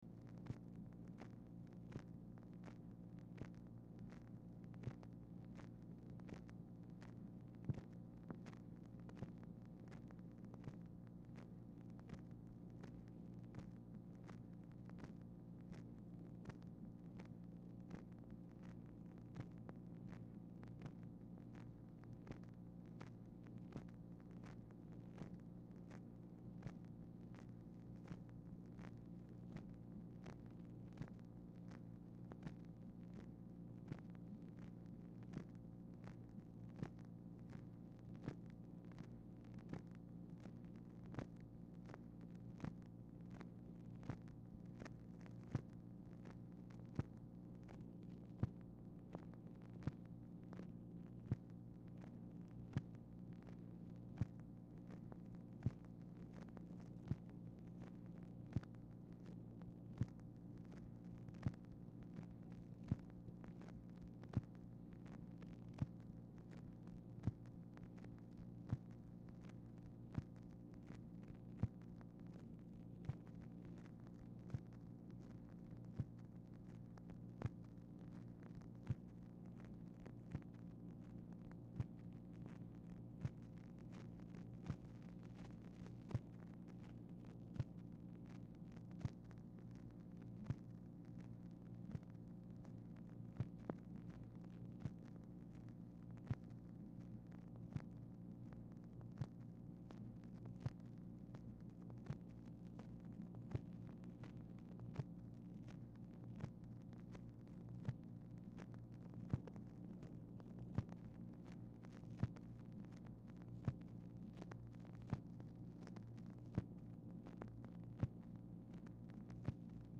Telephone conversation # 4206, sound recording, MACHINE NOISE, 7/10/1964, time unknown | Discover LBJ
Telephone conversation
Format Dictation belt